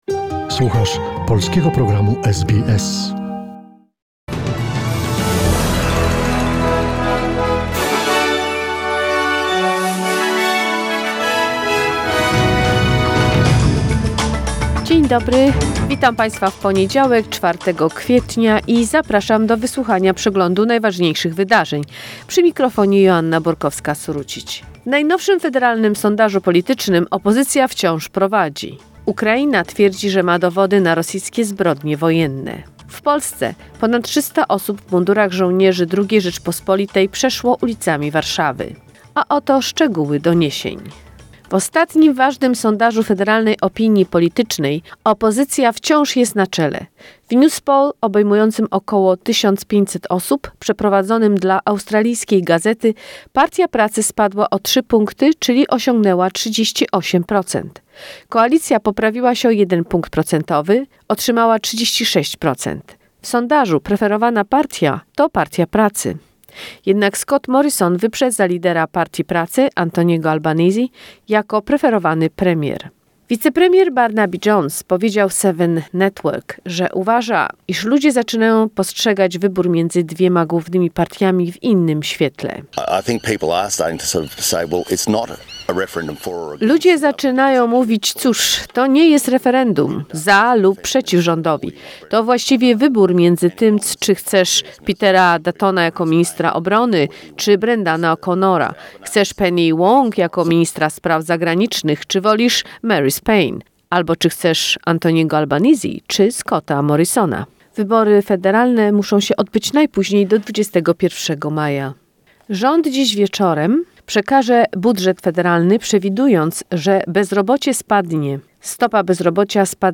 SBS News in Polish, 4 April 2022